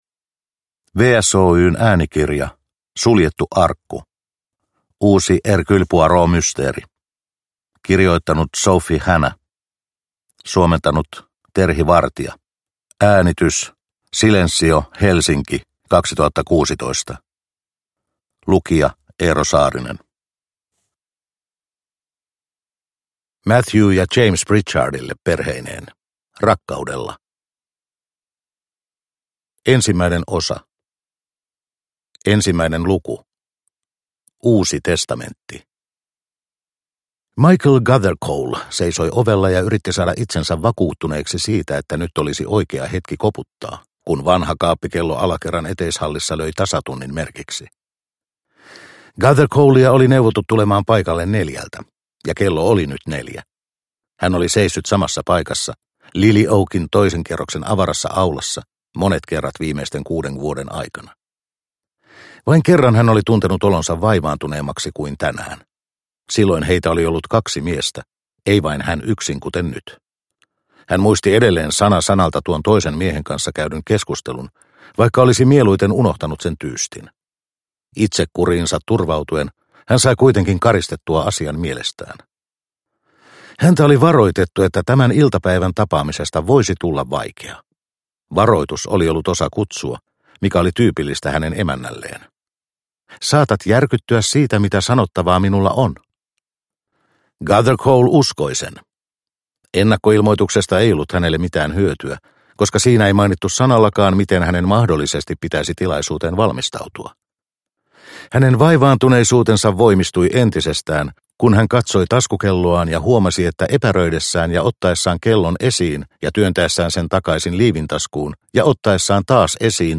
Suljettu arkku – Ljudbok – Laddas ner